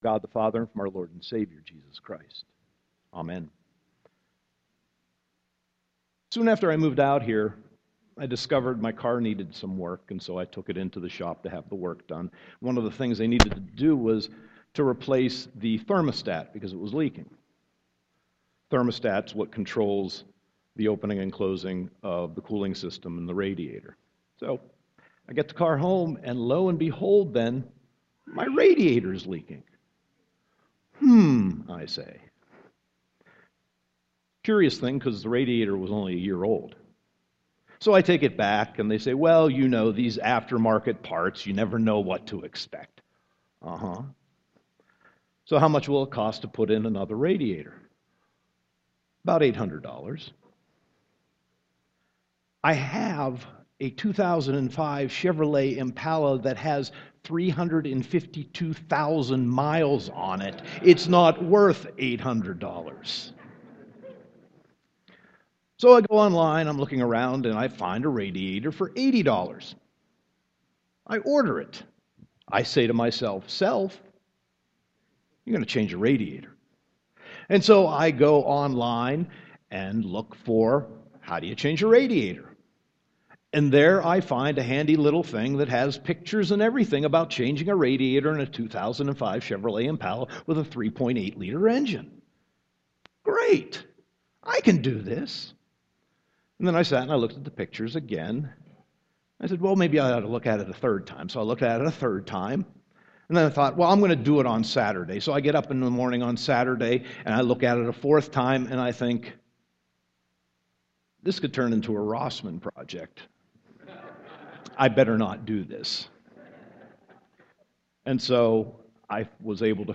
Sermon 10.26.2014